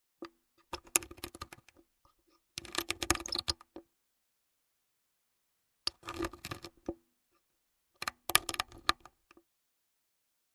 Film transport
0156_Filmtransport.mp3